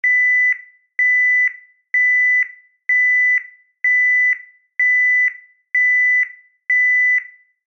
Задний ход грузовика альтернативный вариант